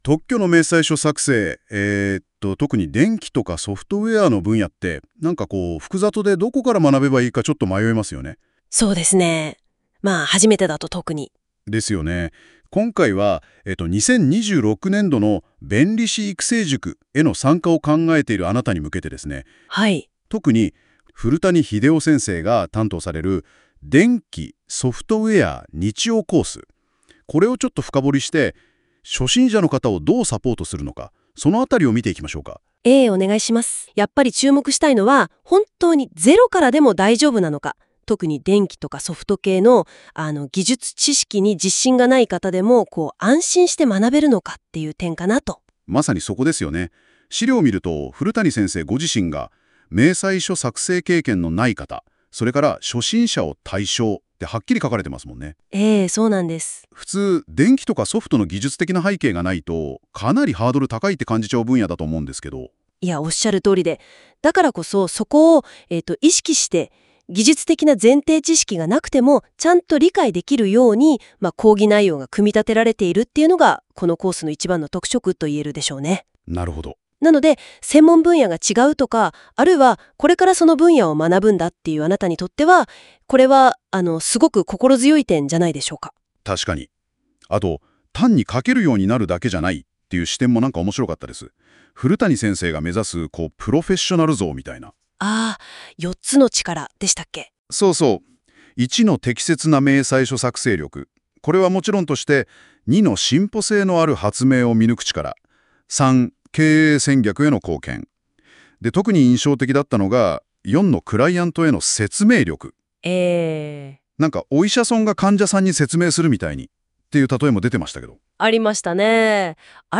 音声による概要説明はこちらです
音声解説（約７分）　弁理士育成塾（ソフト・電気クラス）概要